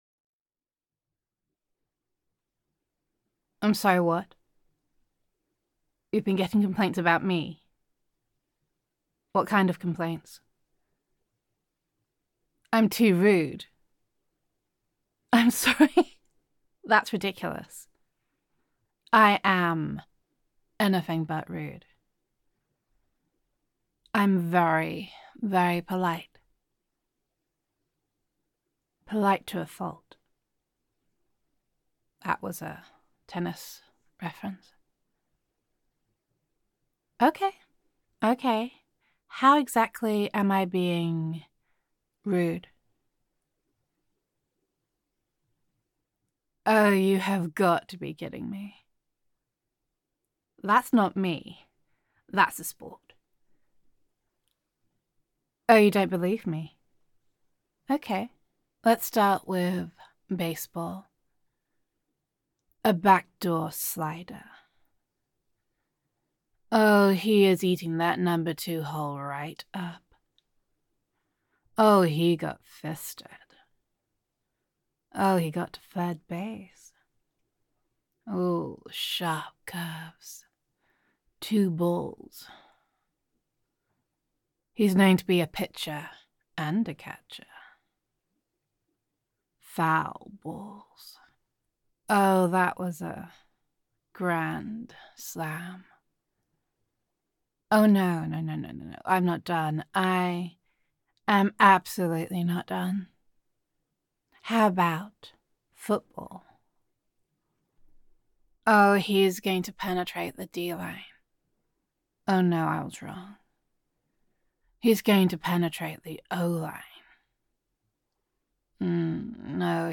[F4A] What Did You Expect When It Is All About the Balls [There Have Been Complaints][I Am Not Rude][Sports Innuendo][Sultry Sports Commentator][Gender Neutral][A Polite Sports Commentator Proves Her Innocence]